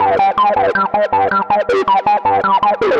Index of /musicradar/future-rave-samples/160bpm
FR_Cheeka_160-G.wav